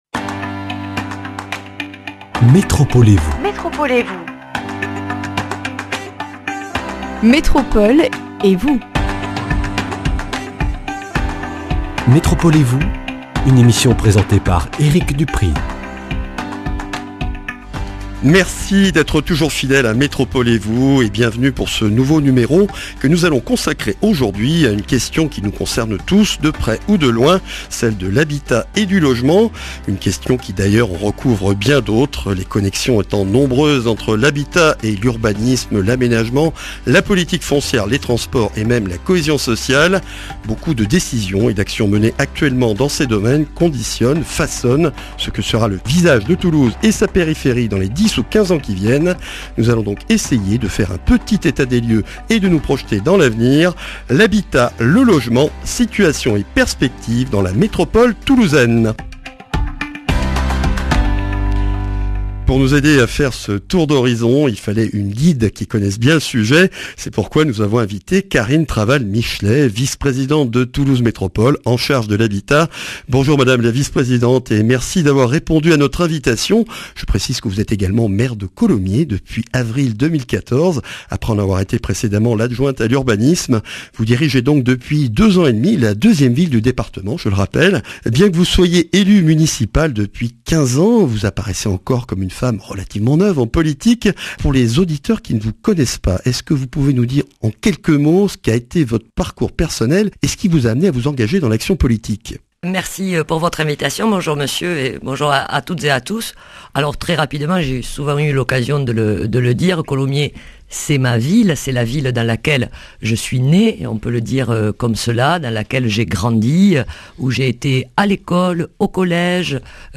État des lieux et programmes en cours avec Karine Traval-Michelet, Maire de Colomiers, 2ème Vice-présidente de Toulouse Métropole chargée de l’Habitat.